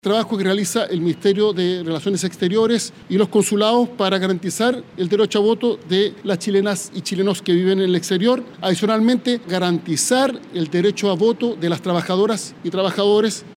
Así lo detalló el ministro del Interior, Álvaro Elizalde, quien agregó que habrá fiscalizaciones sobre el feriado legal para el día de la elección y así resguardar el lapso de tres horas que tiene cada trabajador para votar.